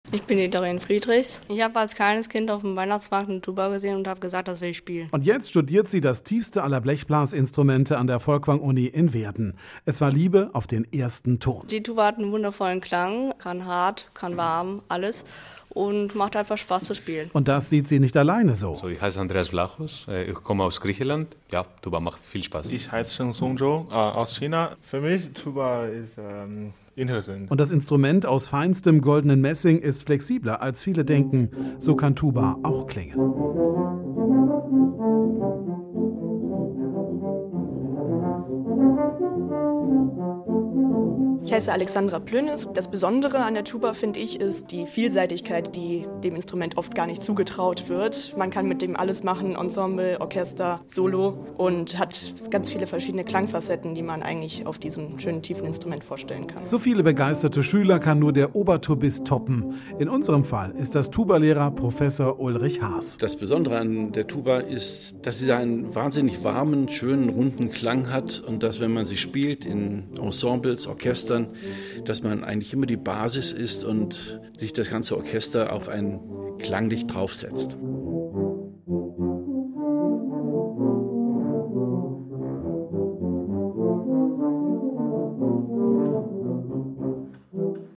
Wir haben die Tuba-Klasse an der Folkwang-Uni vor ihrem Konzert am 22. Mai besucht und sind auf echte Überzeugungstäter gestoßen.
bme-tuba.mp3